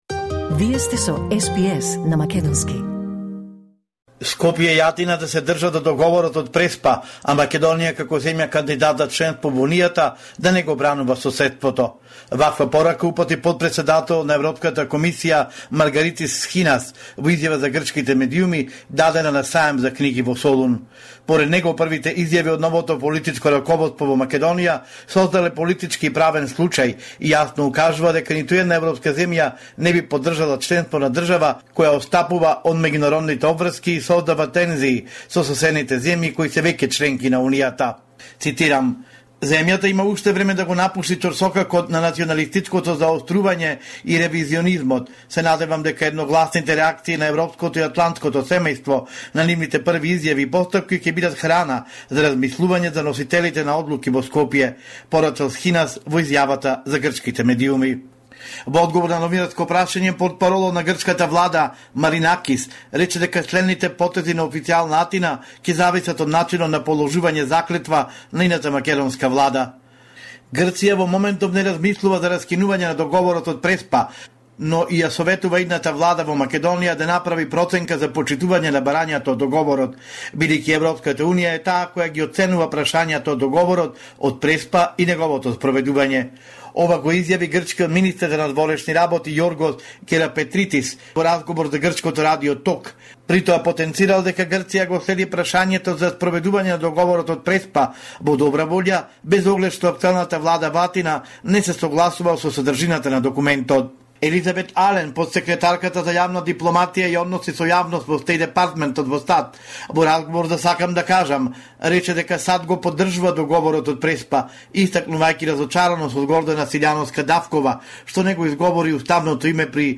Homeland Report in Macedonian 21 May 2024